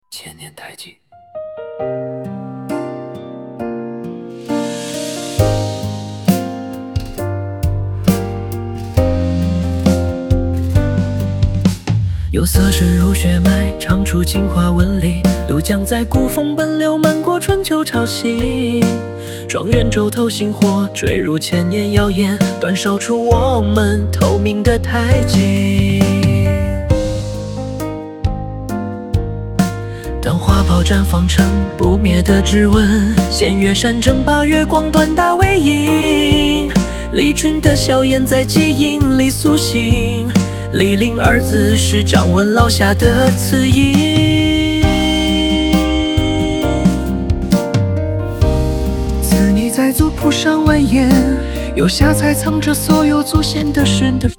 史诗民谣